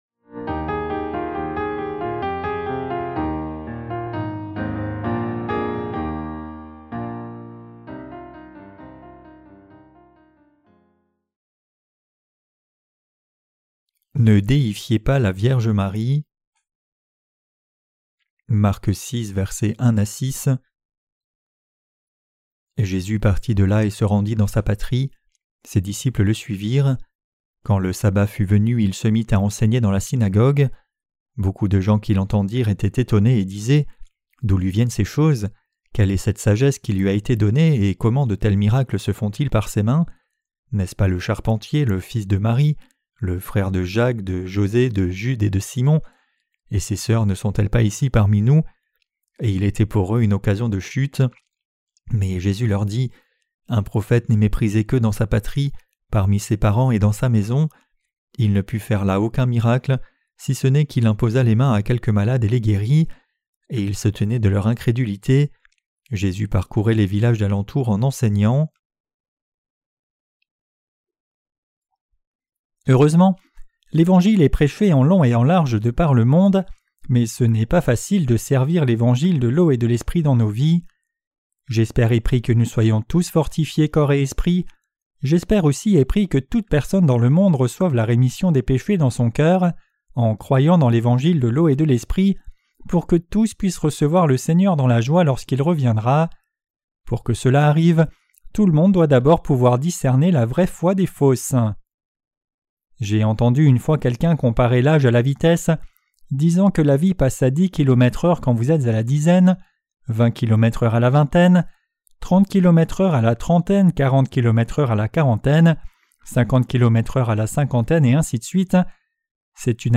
Sermons sur l’Evangile de Marc (Ⅰ) - QUE DEVRIONS-NOUS NOUS EFFORCER DE CROIRE ET PRÊCHER? 14.